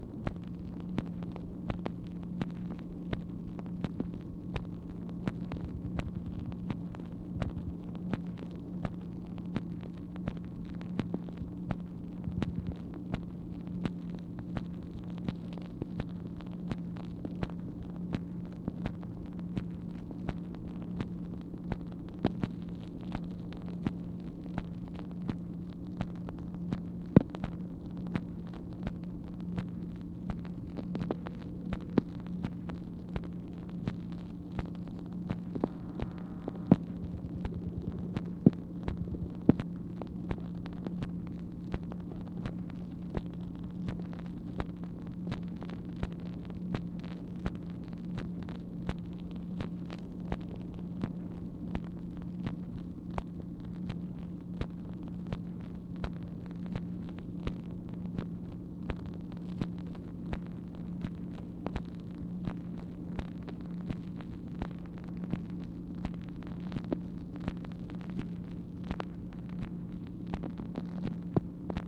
MACHINE NOISE, March 25, 1964
Secret White House Tapes | Lyndon B. Johnson Presidency